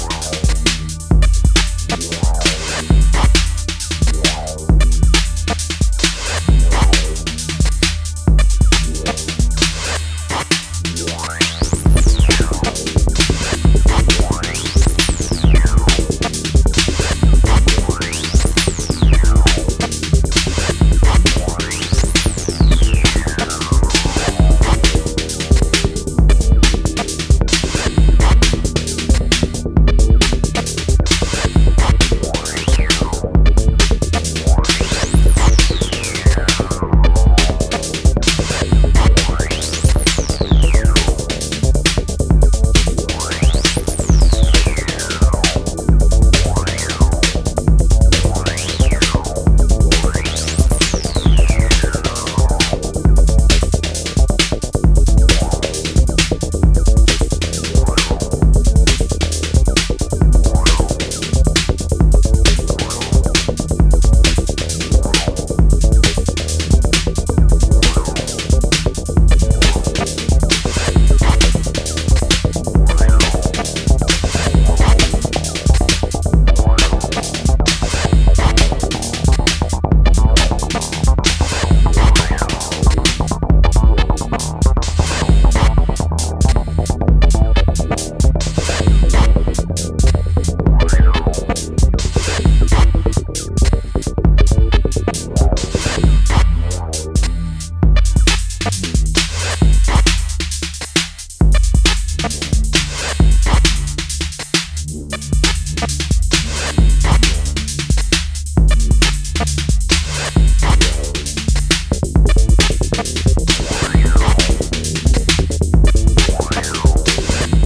Acidic electroid cuts
Electro House Techno